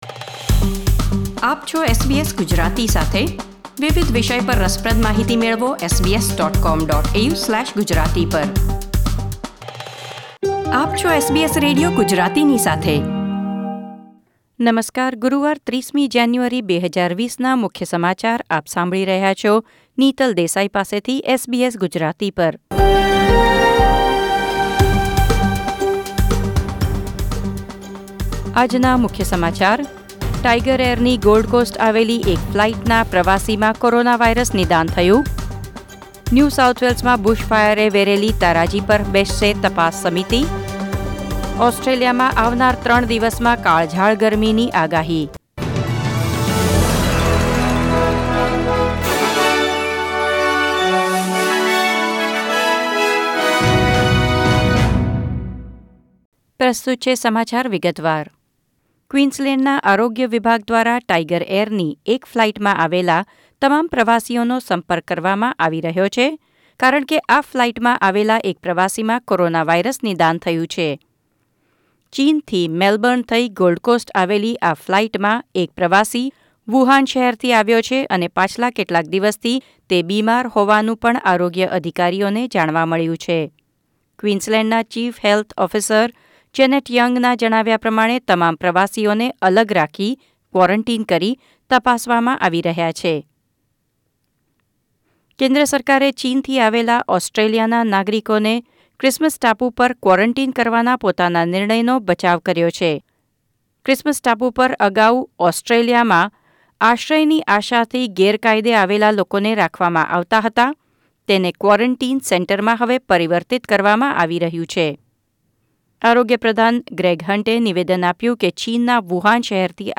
30 જાન્યુઆરી ૨૦૨૦ના મુખ્ય સમાચાર